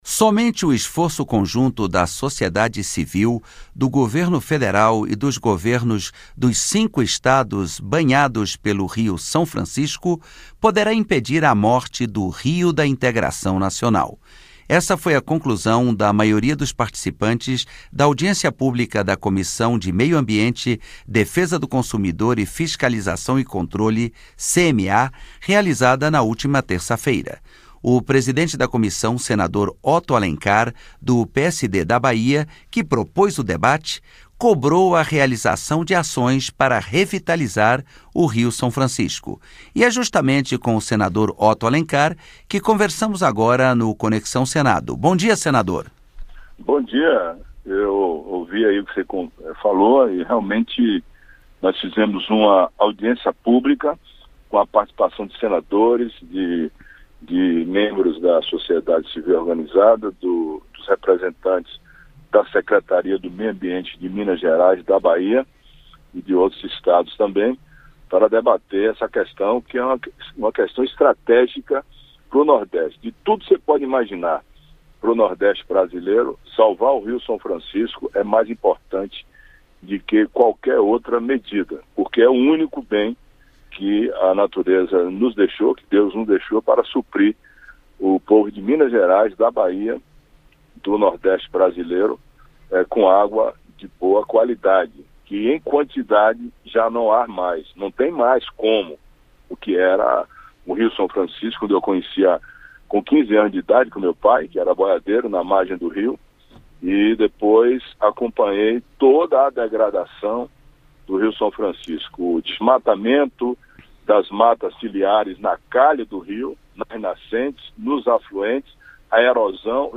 O senador conversou sobre o assunto